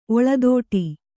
♪ oḷadōṭi